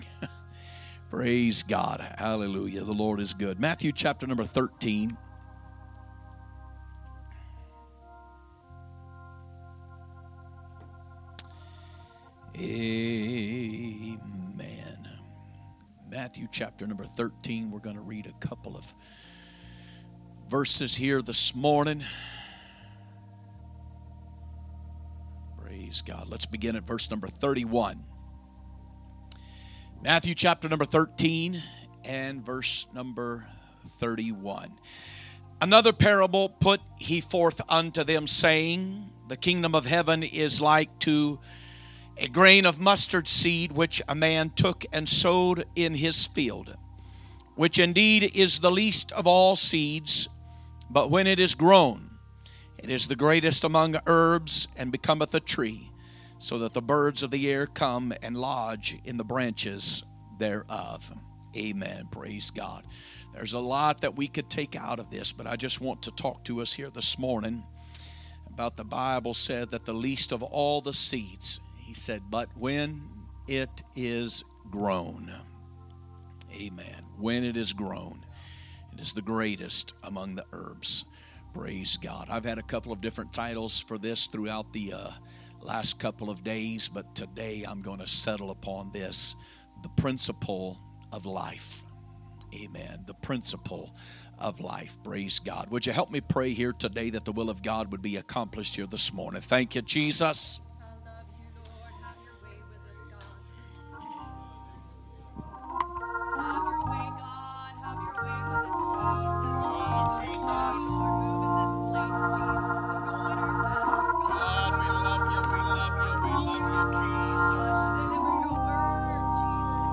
A message from the series "2025 Preaching." Sunday Morning Message